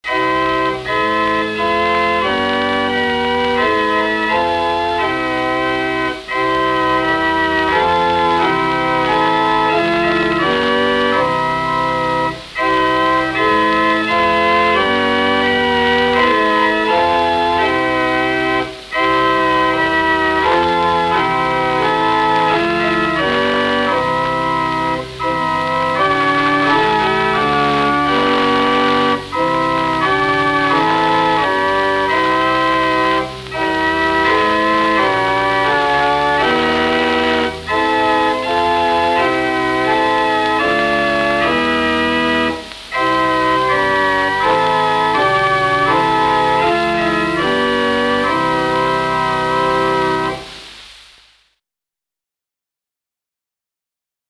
Vorgeschichte - die Kemper-Orgel
Ihre Einweihung erfolgte am 27.3.1938. Sie enthielt 17 Register auf zwei Manuale und ein Pedal verteilt.
Einen akustischen Eindruck von dieser Orgel (Tonaufnahme aus den vierziger Jahren) finden Sie